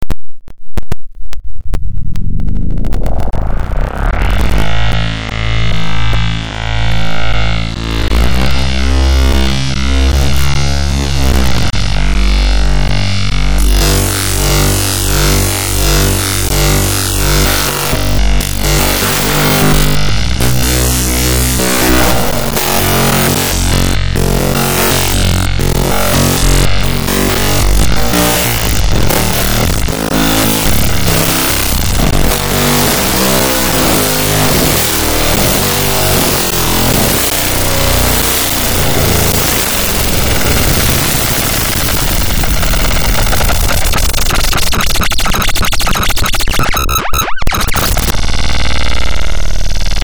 These audio recordings were recorded by other software while CodeSimian played audio on the speakers.
Here are some sounds made with equations + moving the mouse and clicking its buttons.
download Electric Sound 2
Copy/paste the above code into CodeSimian.JAR to play simulated electric guitar with your mouse.